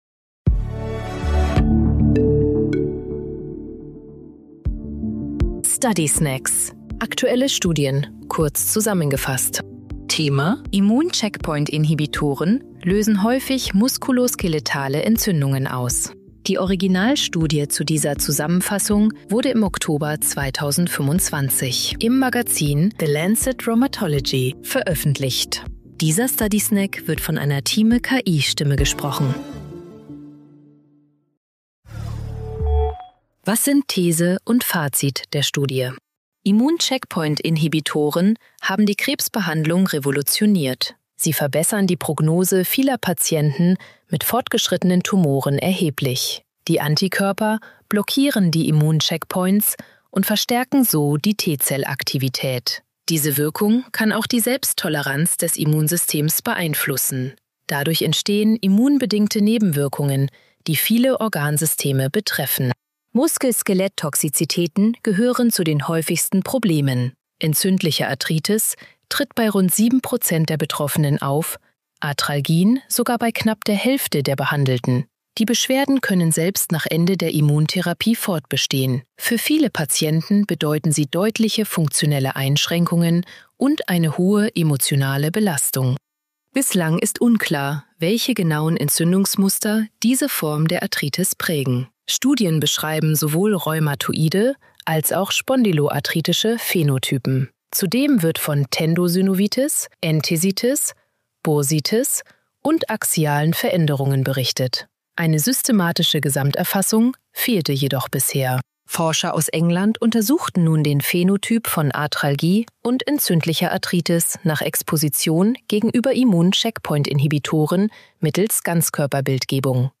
Intelligenz (KI) oder maschineller Übersetzungstechnologie